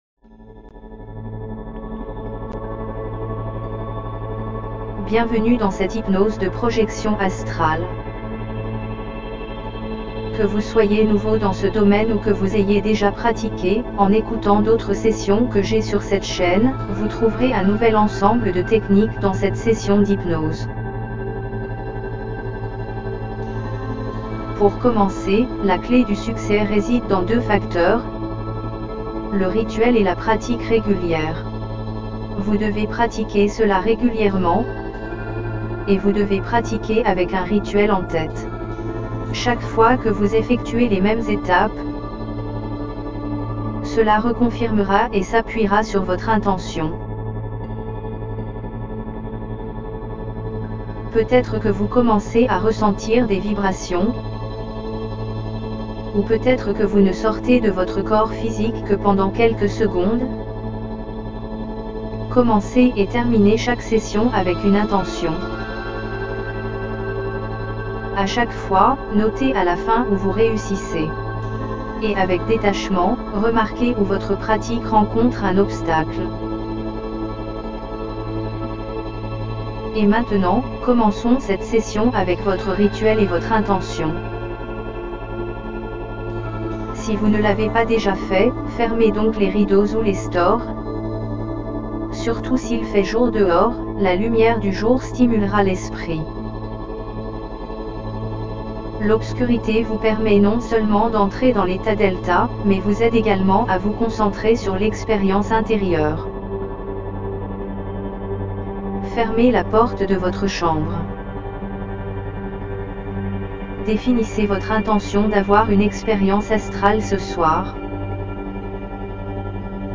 Hypnose par Projection Astrale avec de NOUVELLES Techniques
OBEAstralProjectionHypnosisNEWTechniquesFR.mp3